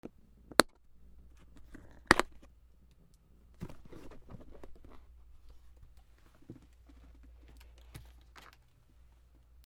/ H｜バトル・武器・破壊 / H-75 ｜プラスチック
プラスチックの箱のツメ(MD421の箱)